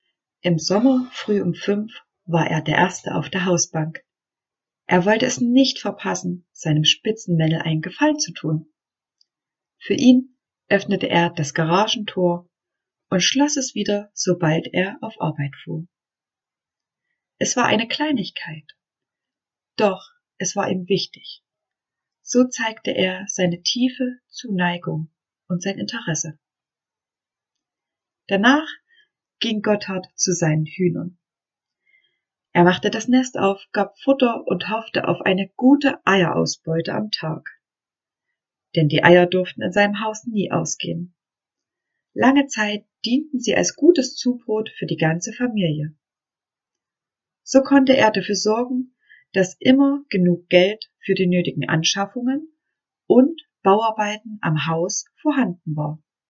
Nebenstehend können Sie sich mit diesem kleinen Auszug aus einer Lebensrede einen Eindruck von meiner Stimme machen.